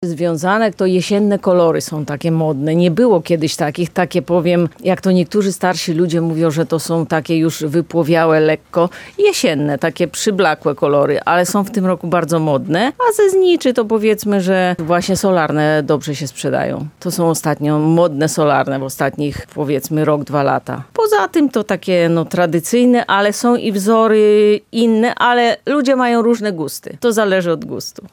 Jak mówi sprzedawczyni z jednego z takich punktów, w tym roku wiele osób przy dekoracji grobów wybiera naturalne, jesienne barwy.